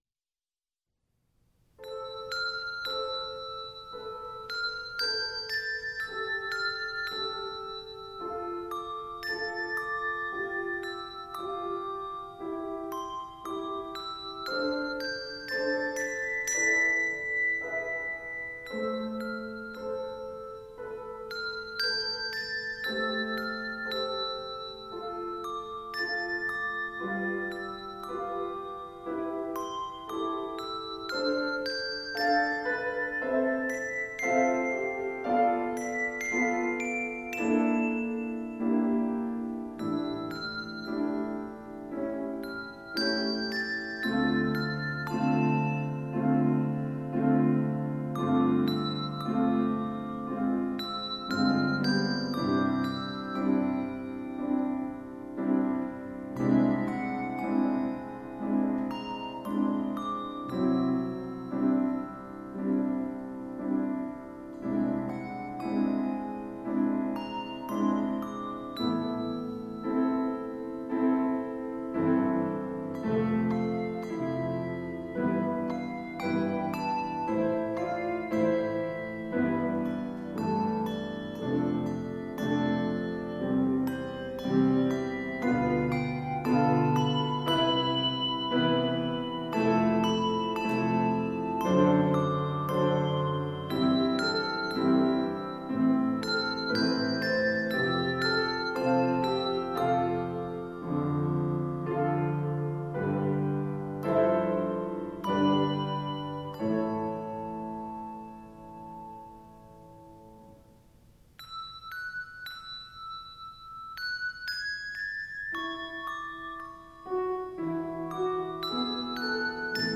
Voicing: Handbells 4 Octave